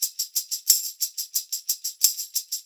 90 TAMB2.wav